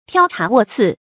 挑茶斡刺 tiāo chá wò cì
挑茶斡刺发音
成语注音ㄊㄧㄠ ㄔㄚˊ ㄨㄛˋ ㄘㄧˋ